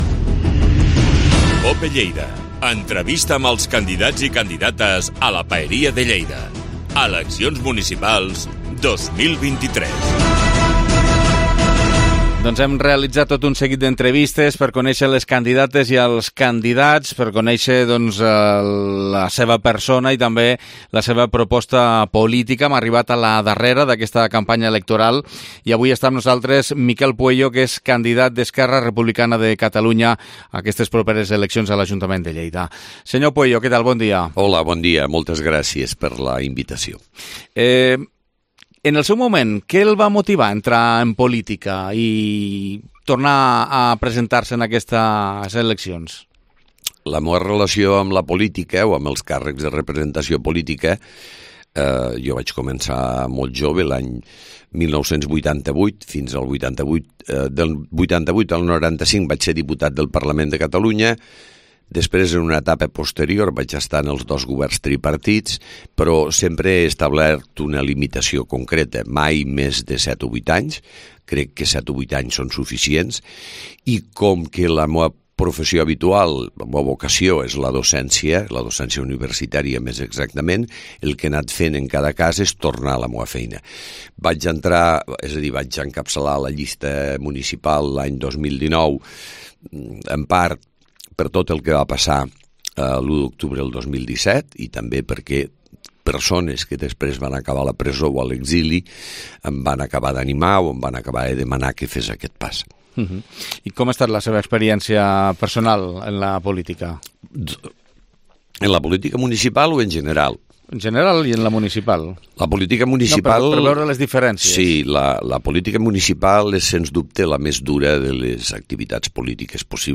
Entrevista Campanya Electoral 2023 - Miquel Pueyo - ERC